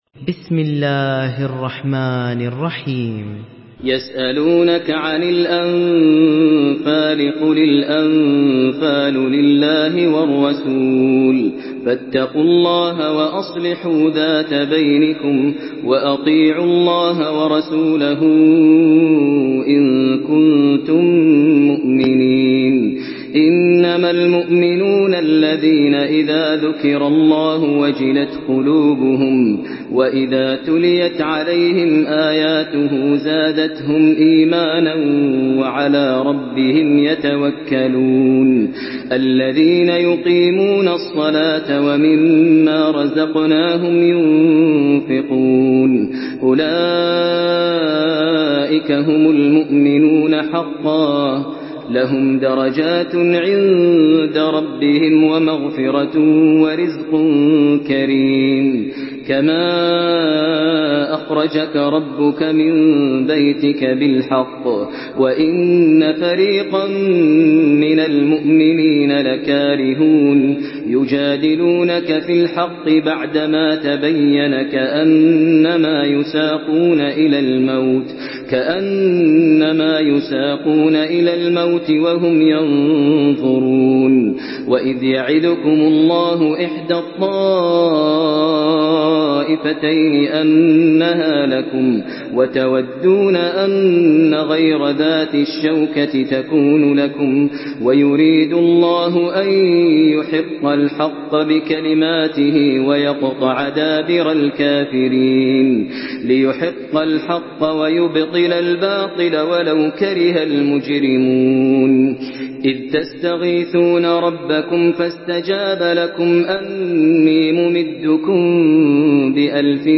Surah আল-আনফাল MP3 by Maher Al Muaiqly in Hafs An Asim narration.
Murattal Hafs An Asim